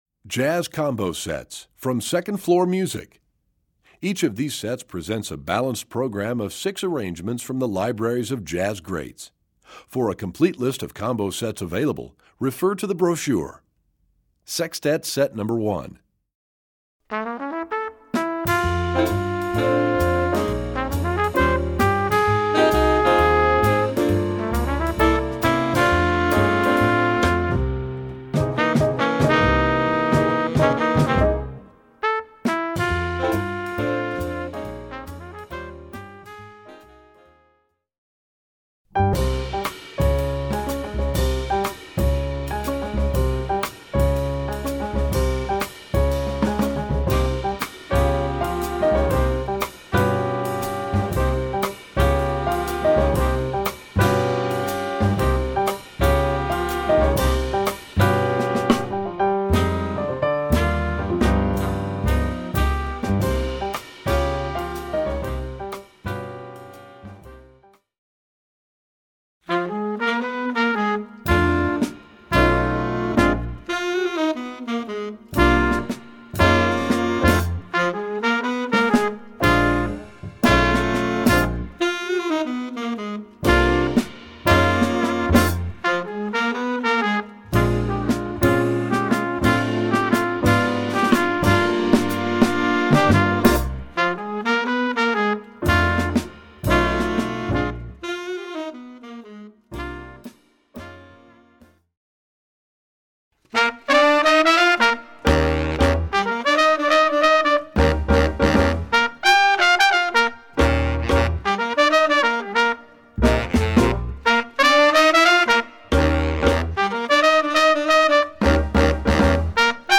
Voicing: Combo Collection